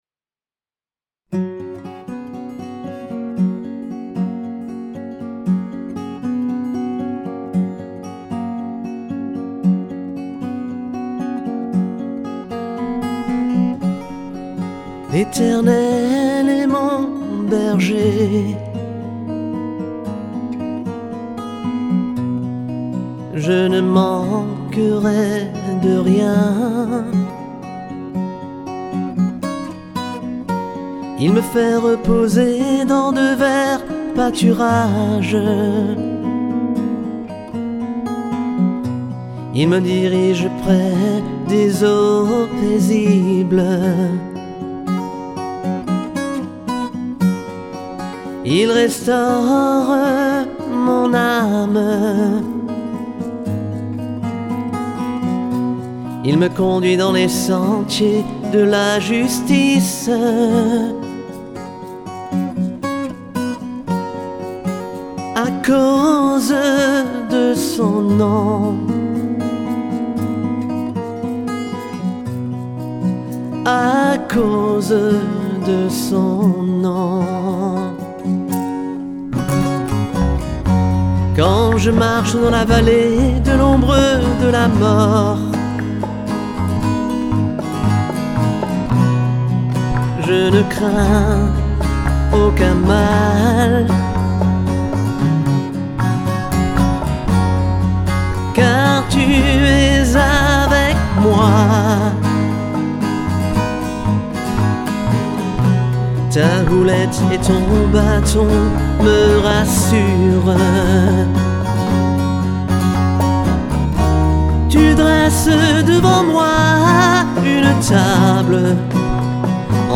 Voix
Basse
Percussions